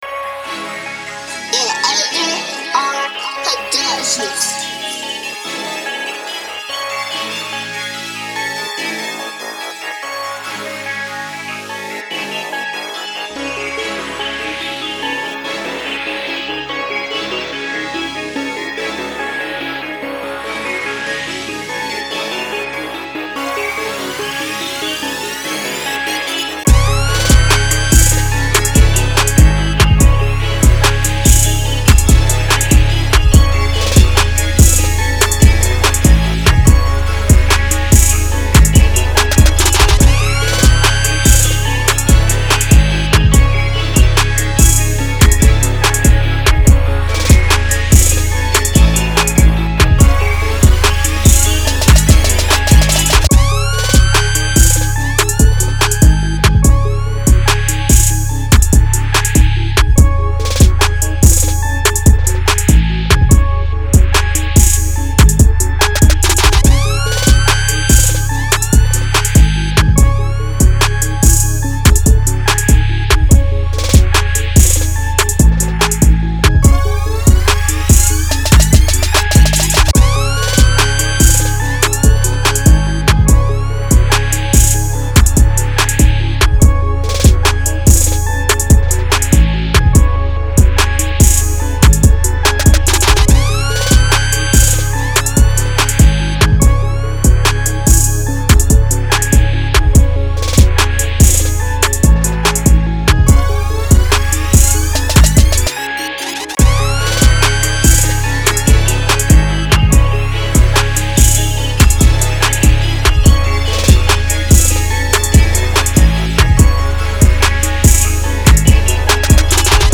Featuring 13 explosive tracks